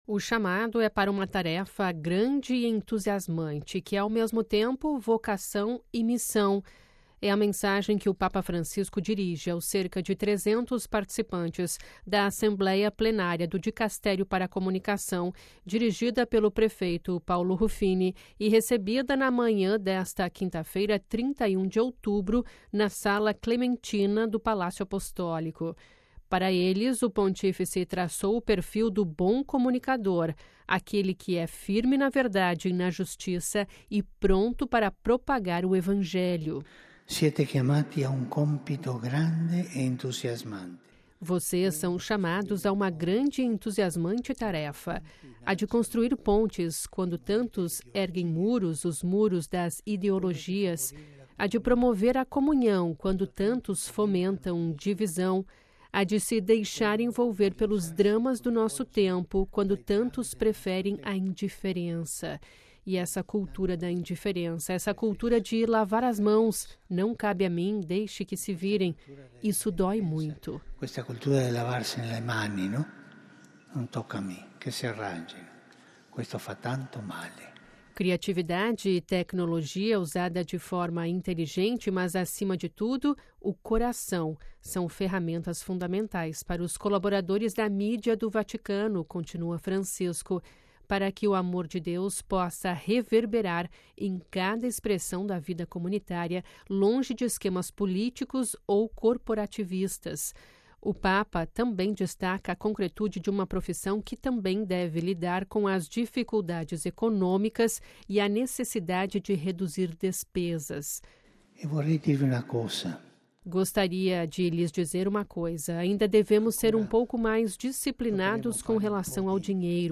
Discurso Papa Francisco
Ouça a reportagem com a voz do Papa e compartilhe: